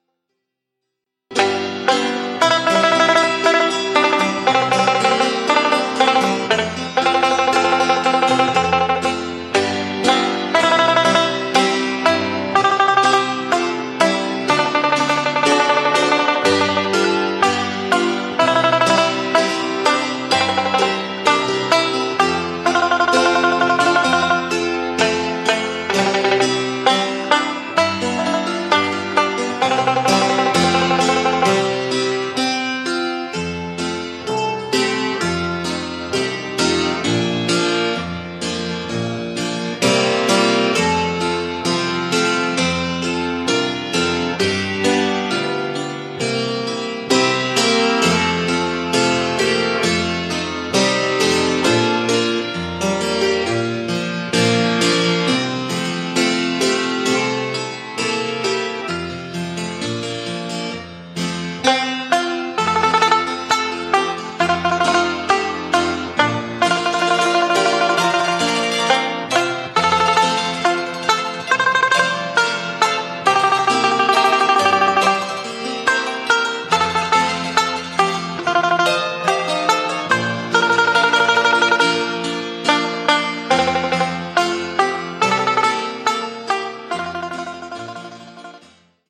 Traditional Folk Song
Listen to "Red River Valley" by an unnamed performer (mp3)